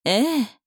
大人女性│女魔導師│リアクションボイス
うなずく